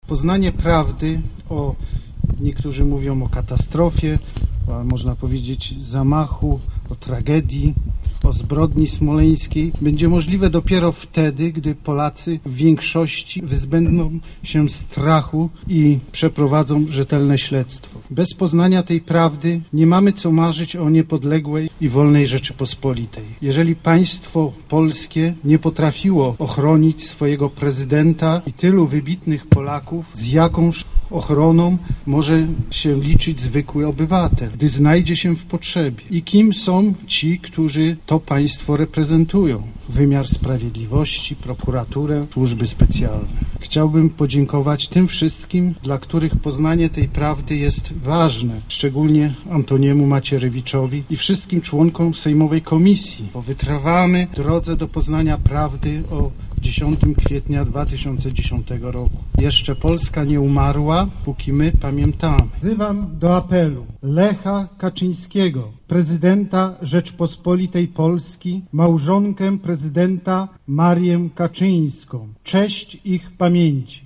Uroczyste nabożeństwo odprawiono w kościele Matki Boskiej Częstochowskiej, następnie pod pamiątkową tablicą na błoniach zapalono znicze, złożono kwiaty.
Później uczestnicy obchodów przeszli na Wzgórze Zamkowe, gdzie pod tablicą smoleńską z wyrytymi nazwiskami ofiar odczytano apel tragicznie zmarłych.